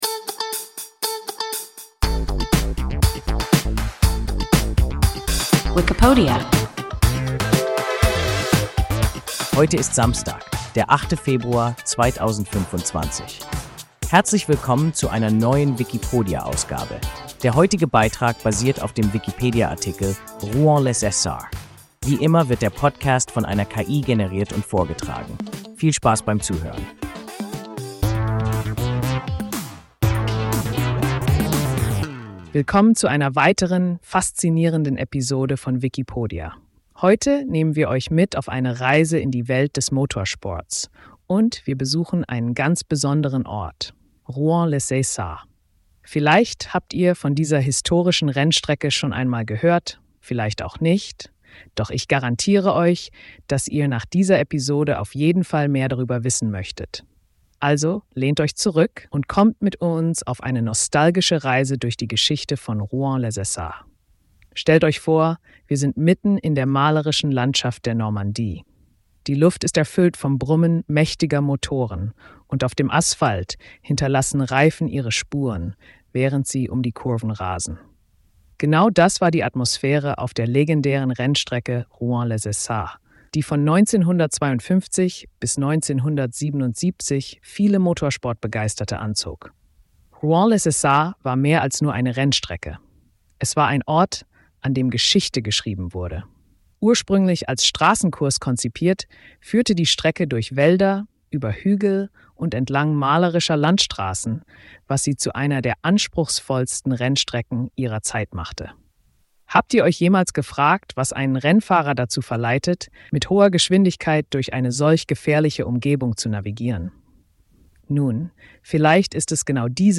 Rouen-les-Essarts – WIKIPODIA – ein KI Podcast